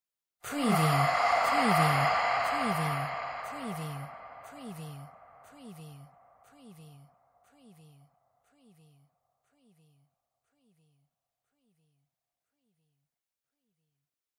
Surreal whisper pitch rev 016
Breathy vocal flooded with reverb.
Stereo sound effect - Wav.16 bit/44.1 KHz and Mp3 128 Kbps
previewSCIFI_WHISPERS_SPOOKY_PITCHREV_WBHD015.mp3